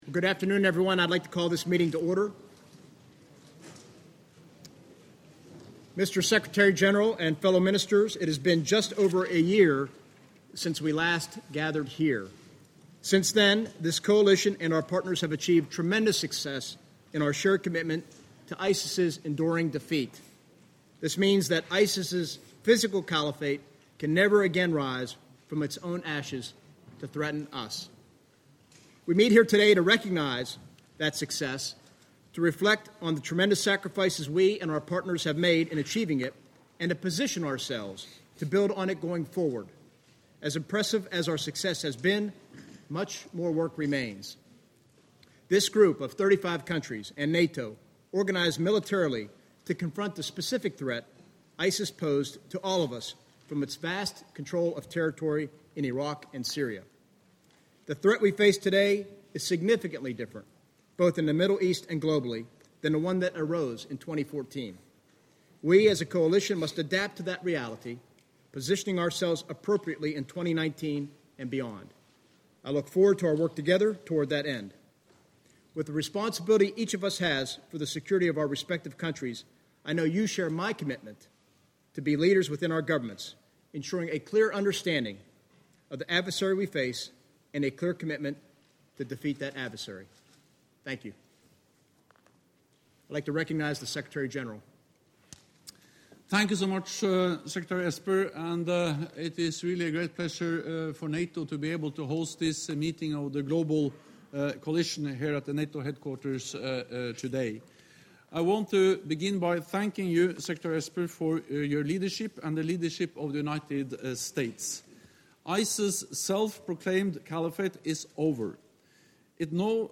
Defence Ministers of nations contributing to the Global Coalition to Defeat ISIS met on Thursday (27 June 2019) at NATO Headquarters, to take stock of continued efforts to ensure ISIS does not return. In his opening remarks, NATO Secretary General Jens Stoltenberg praised the Coalition’s remarkable achievements, and pointed to the importance of training and advising local security forces in the fight against terrorism.